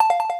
correctNoApplause.ogg